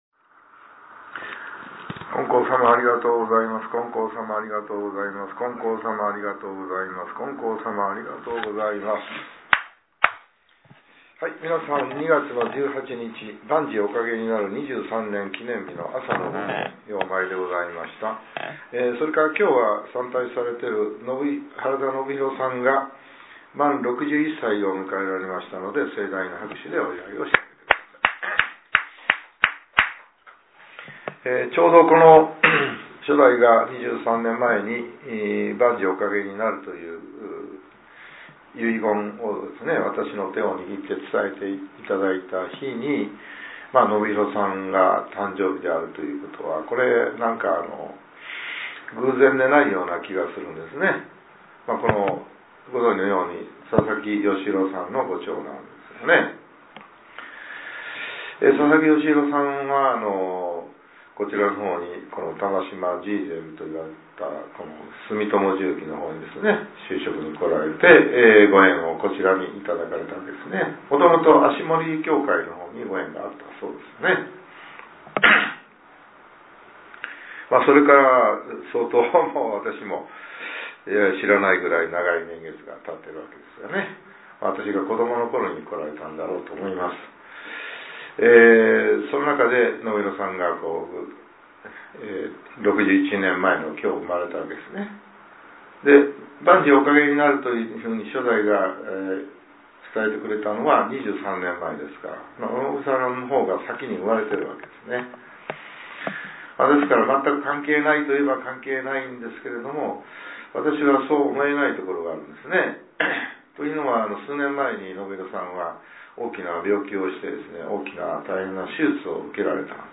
令和８年２月１８日（朝）のお話が、音声ブログとして更新させれています。